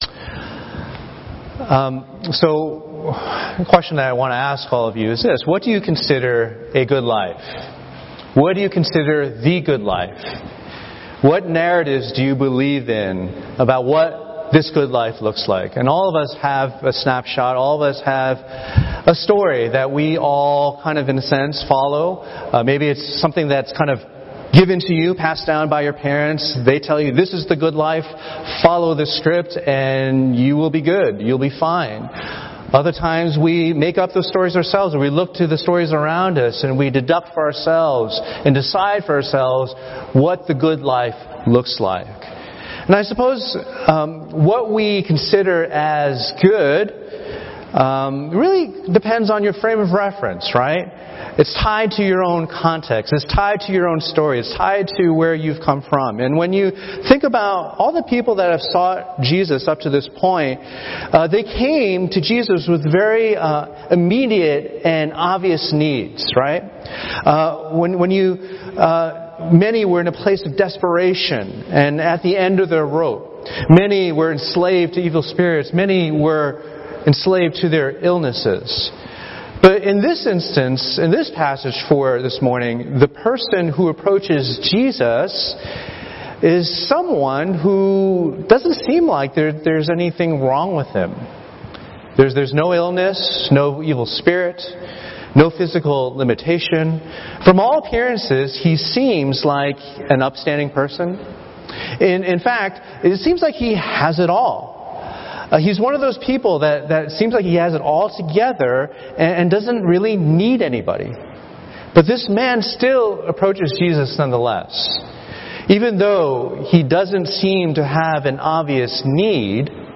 The umbrella term/category for all Sermons from all congregations.